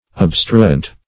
Obstruent \Ob"stru*ent\, n.
obstruent.mp3